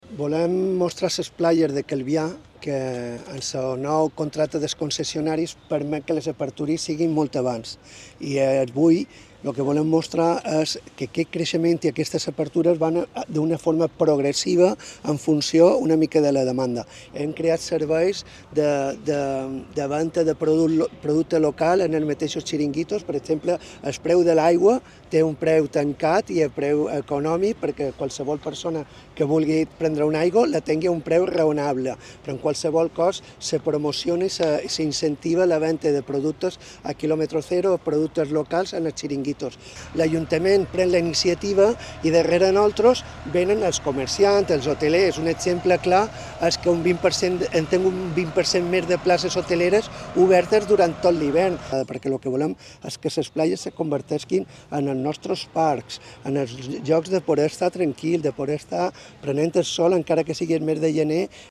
statements-by-the-mayor-of-calvia.mp3